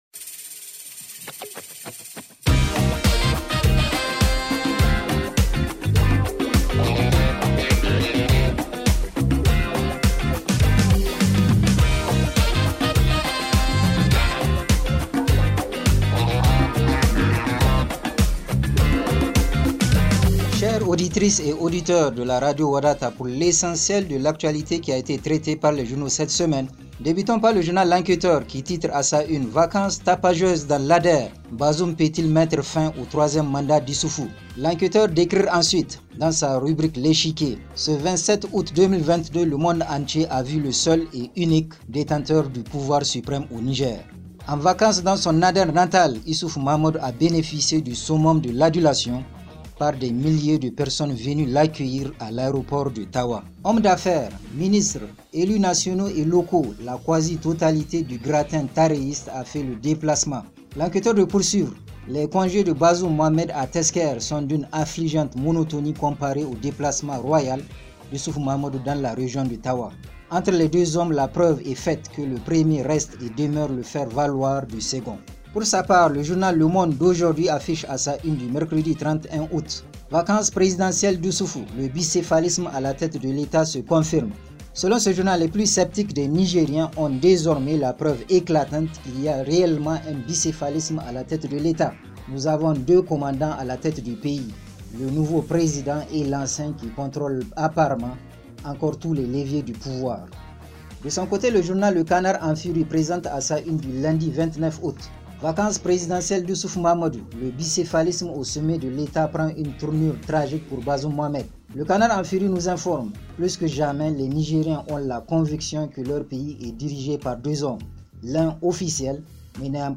Revue de presse en français